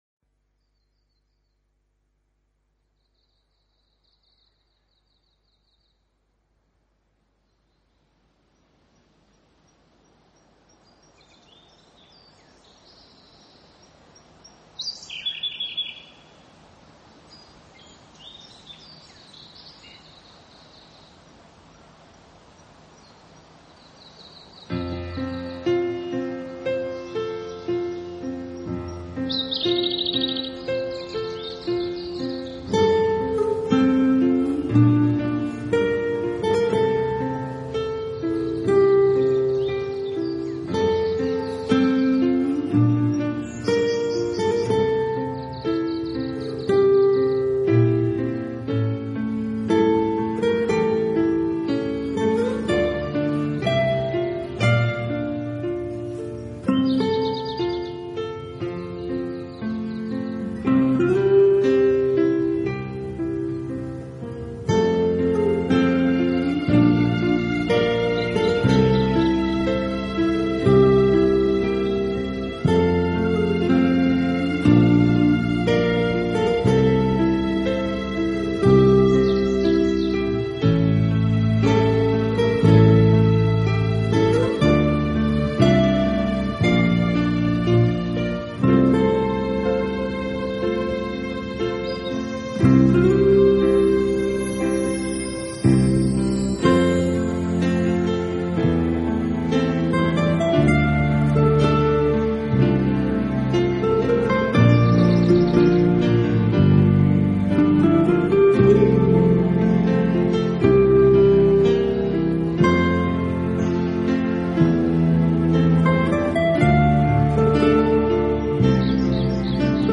合成乐音为演奏的主体，再结合他们所采撷的大自然音效，在这两相结合之下，您可
器配置，使每首曲子都呈现出清新的自然气息。